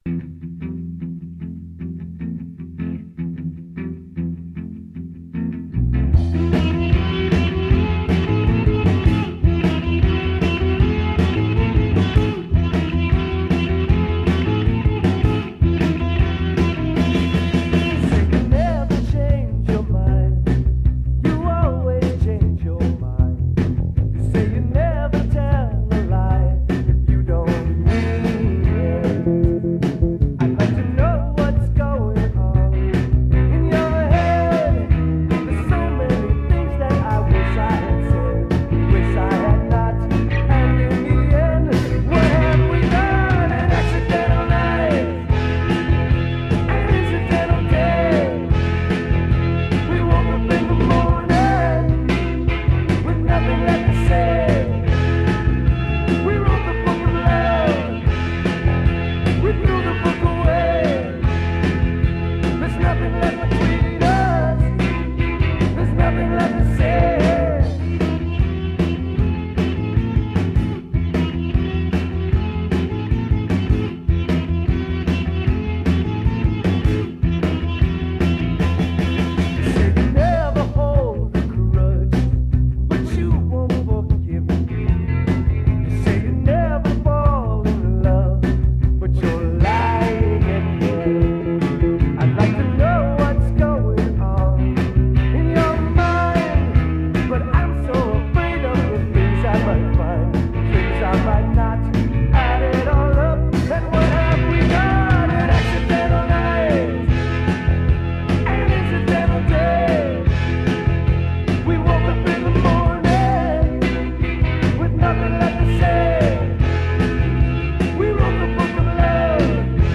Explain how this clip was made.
Most are recorded live at his shows